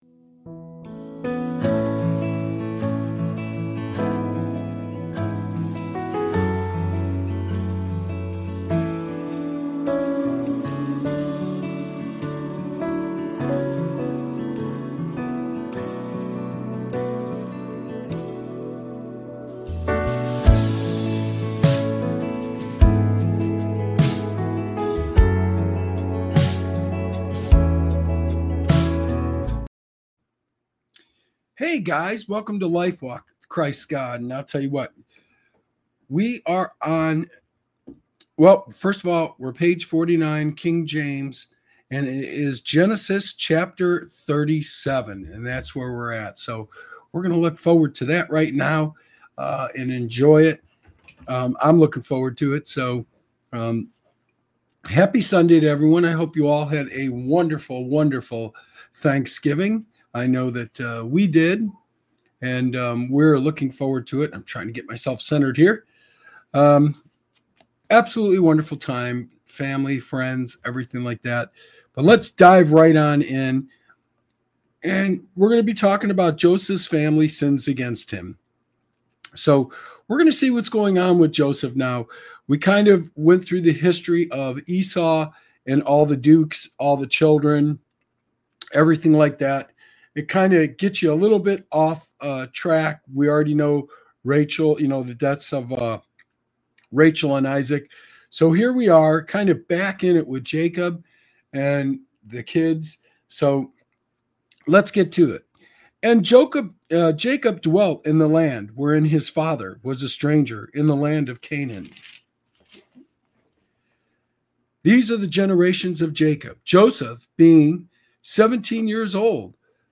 This show offers a reading into the teachings of Jesus Christ, providing insights into the Bible.